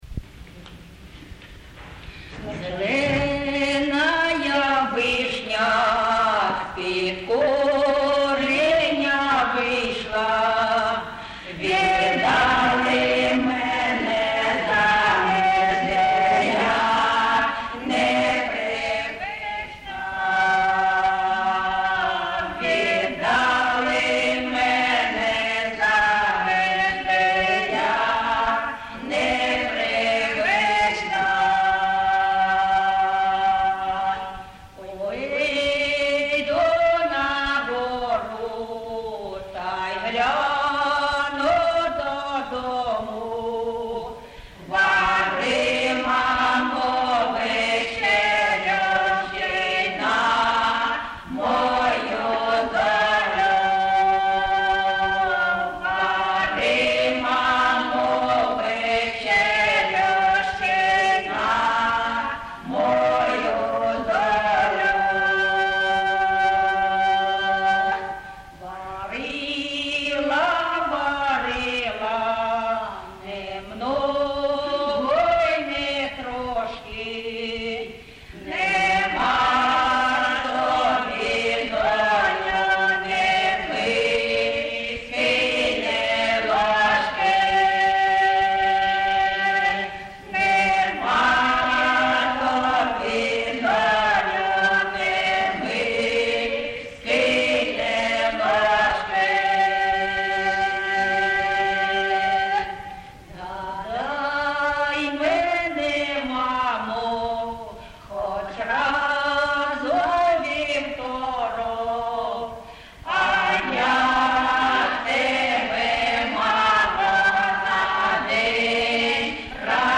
Місце записус-ще Зоря, Краматорський район, Донецька обл., Україна, Слобожанщина
(співають також невідомі виконавиці)